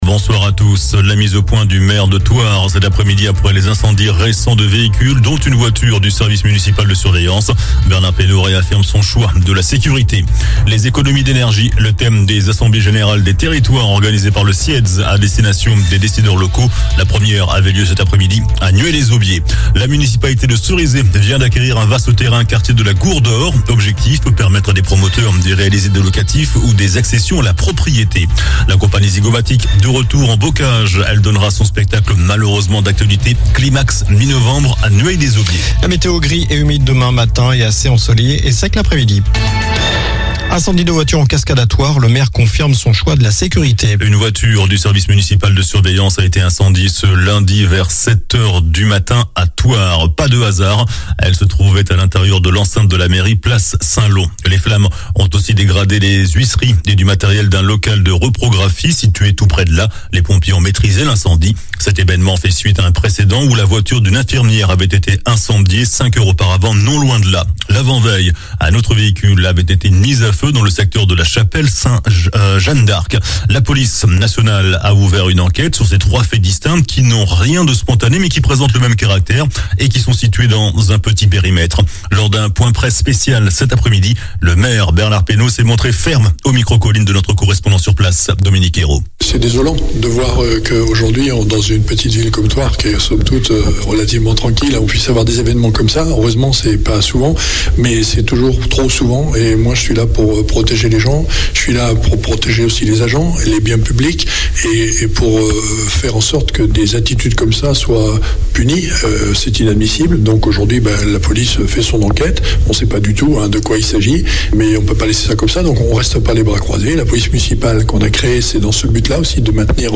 Journal du lundi 10 octobre (soir)